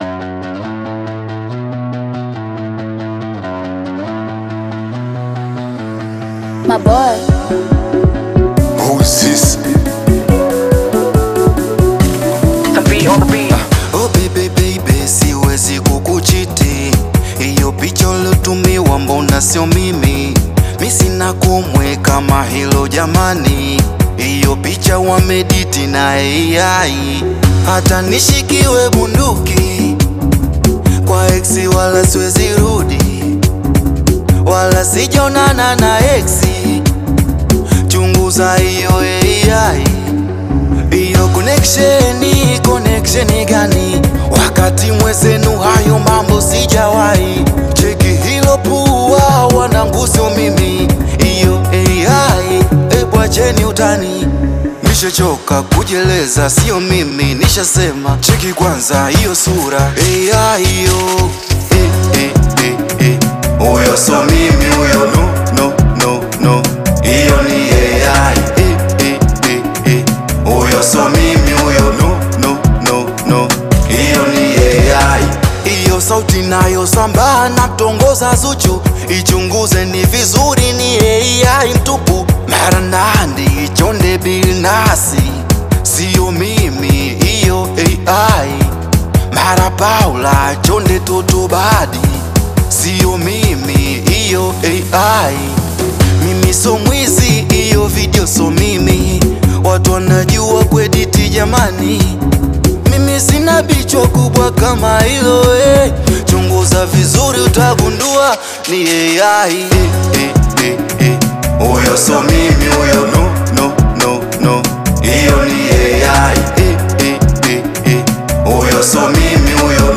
AudioBongo FlavaTanzanian Music